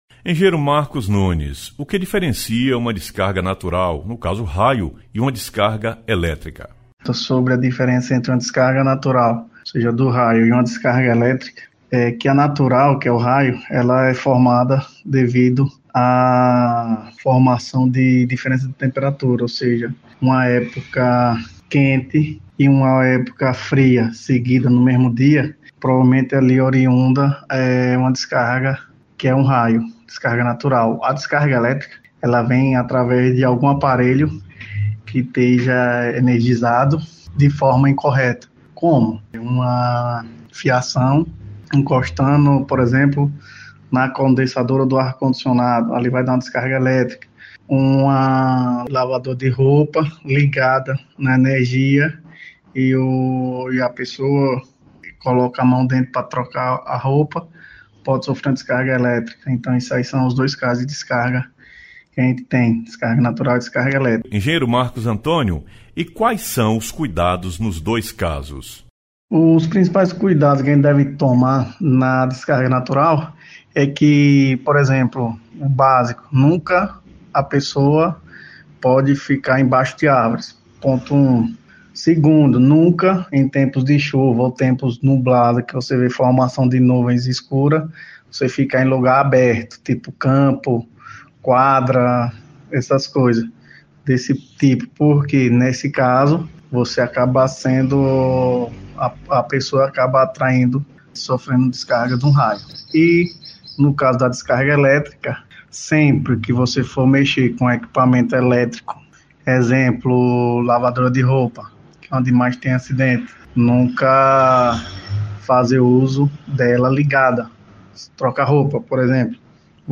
Engenheiro eletricista